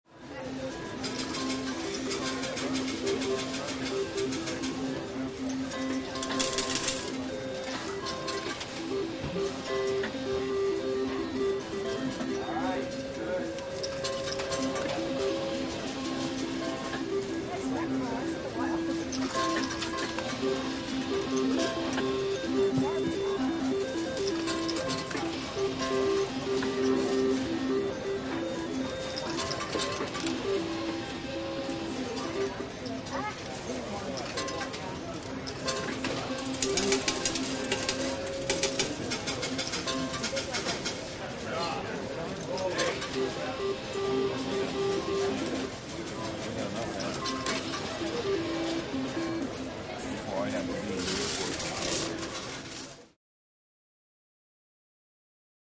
Casino Ambience; Slot Machine Music And Heavy Walla.